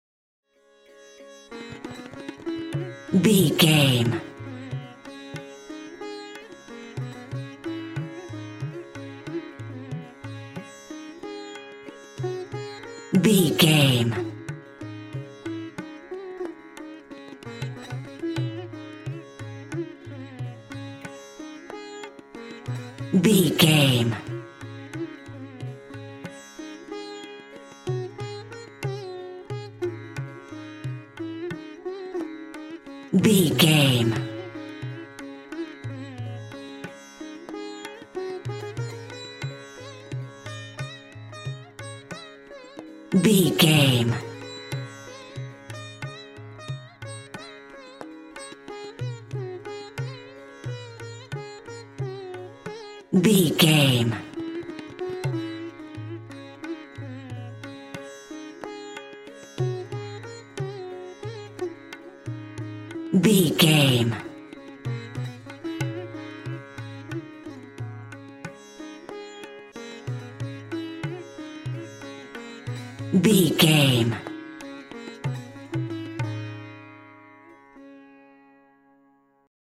Mixolydian
D♭
World Music